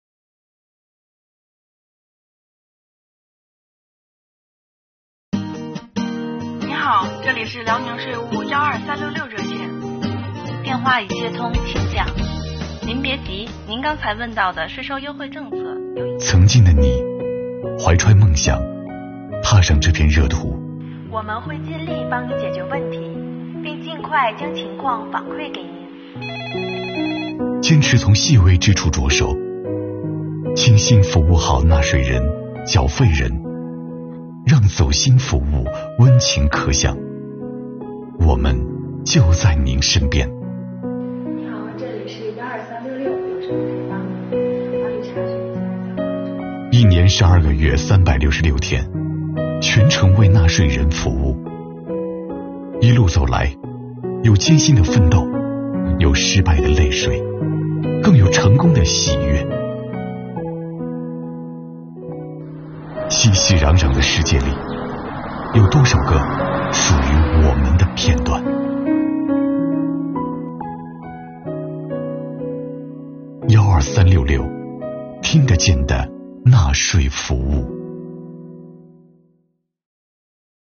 运镜流畅，固定镜头和特写镜头贯穿整部作品，配乐和画面剪辑相得益彰，画面影调层次富于变化，全方位打造了视听效果。